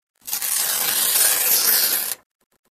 Tiếng Xé túi giấy, Mở bao giấy…
Thể loại: Tiếng động
tieng-xe-tui-giay-mo-bao-giay-www_tiengdong_com.mp3